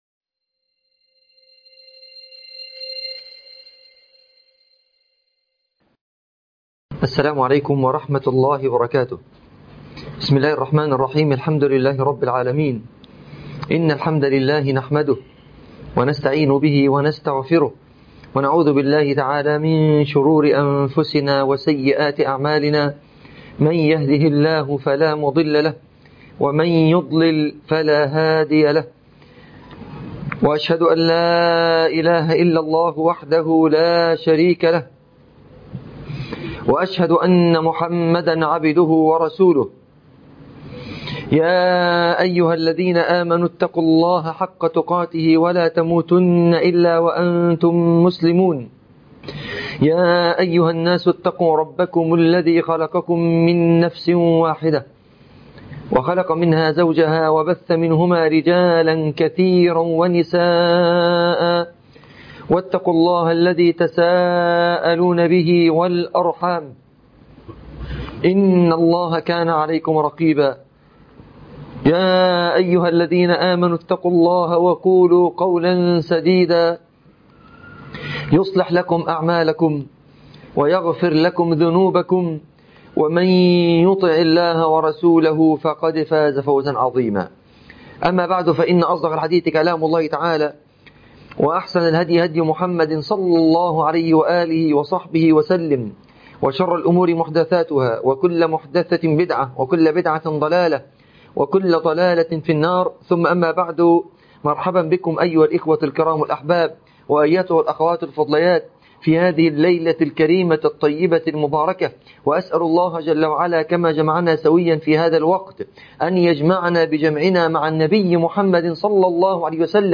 محاضرة 1 || شرح كتاب حلية طالب العلم